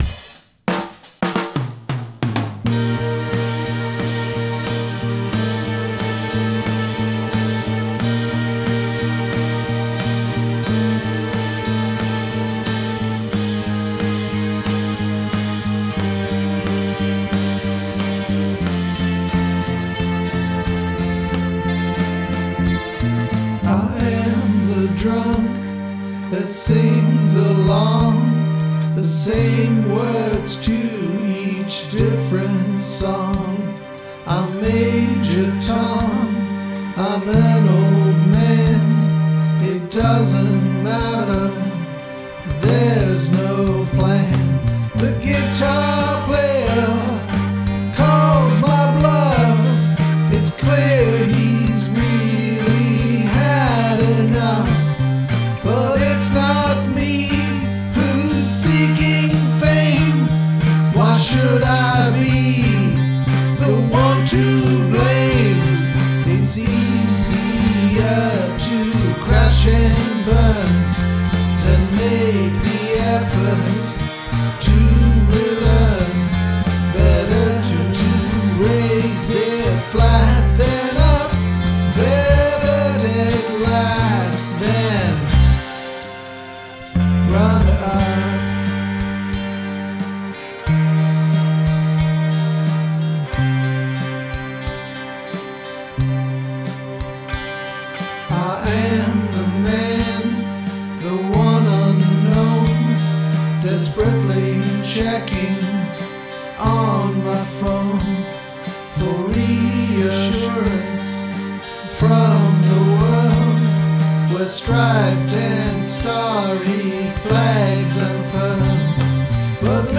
Mellotron
As promised last week, the demo of Runner Up. There are still glitches, as I still learn out to use Logic, rather than old trusty Garageband.
It was a challenge to do, self-indulgent and a lot of fun, particularly with the Mellotron track. Prog-Rock lives on.